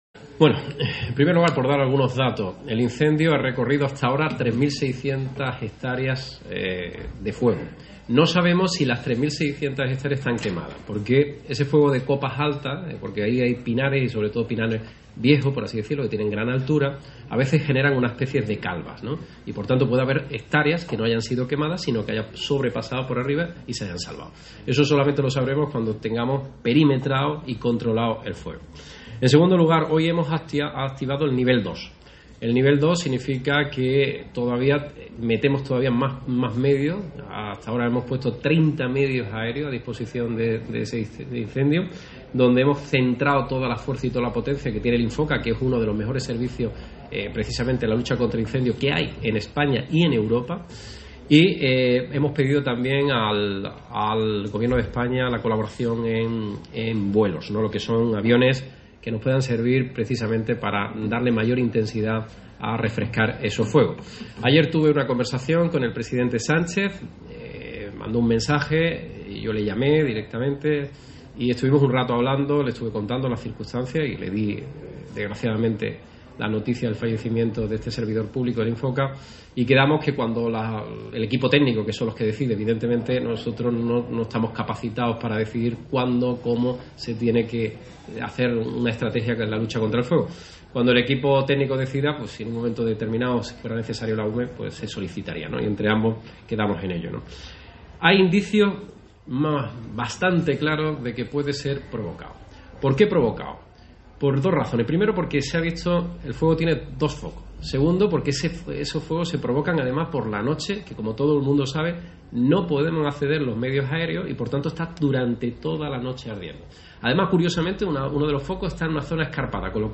Así lo ha aseverado el presidente de la Junta a preguntas de los periodistas durante su participación en el acto de inauguración del nuevo colegio de Educación Infantil y Primaria ‘Al-Ándalus’ de Utrera (Sevilla) coincidiendo con el inicio del curso escolar 2021-2022, tras el que se va a desplazar a la zona del incendio que afecta a la provincia de Málaga para «medir directamente desde allí cómo está la situación» y cómo se puede contribuir a la lucha contra el mismo, según ha confirmado él mismo.